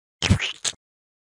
splat.mp3